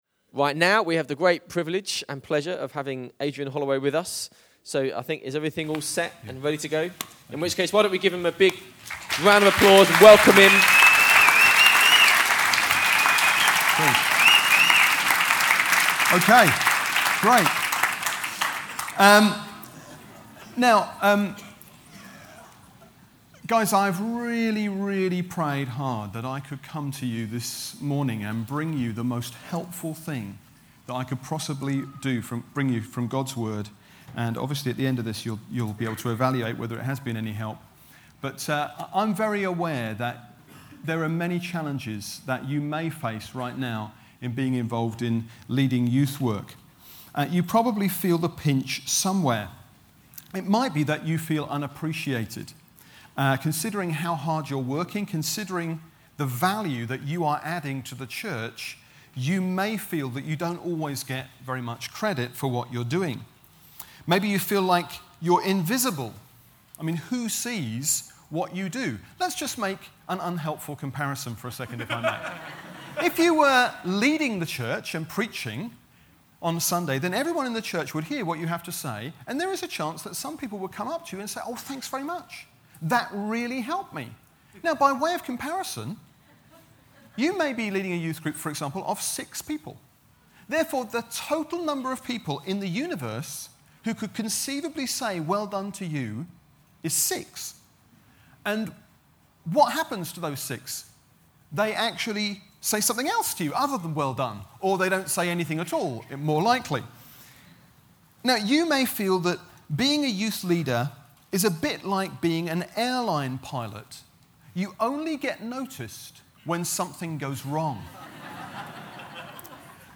1 Cor 15:10 – This is a talk from Newfrontiers’ Newday Youth Workers Conference in 2008.